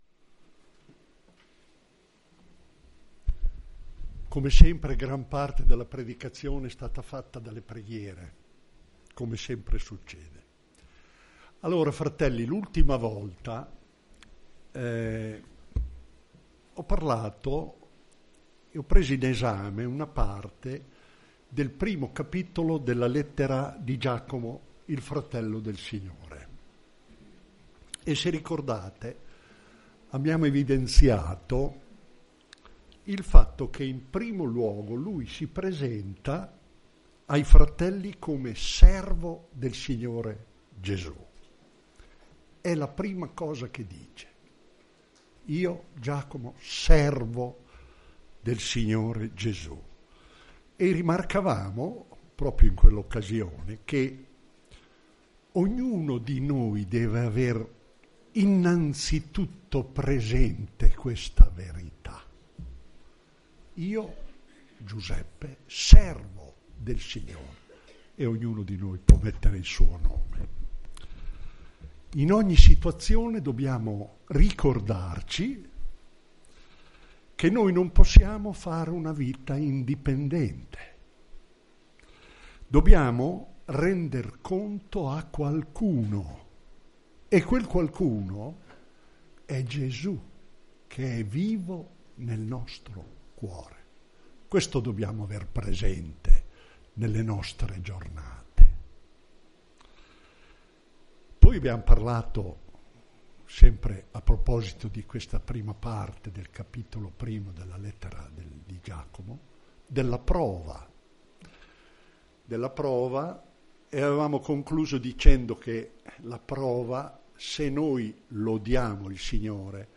Chiesa Cristiana Evangelica - Via Di Vittorio, 14 Modena
Predicazioni